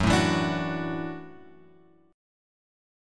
F# Minor 7th Sharp 5th